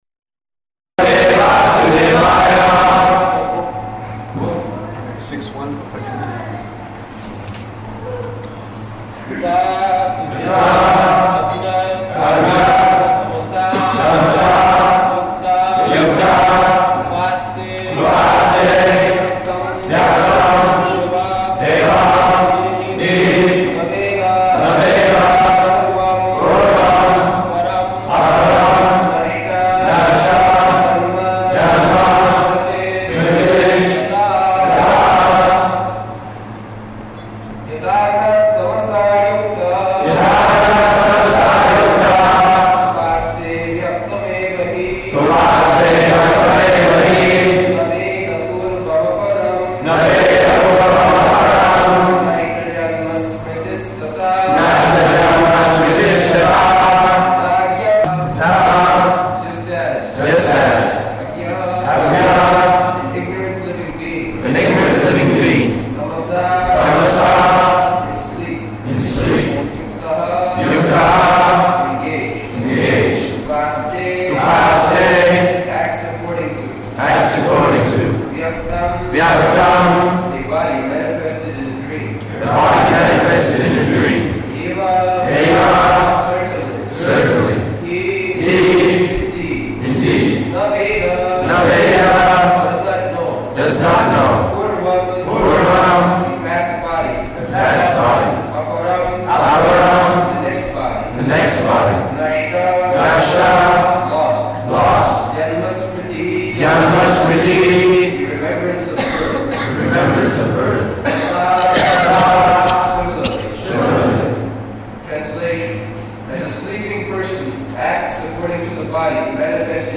Location: Detroit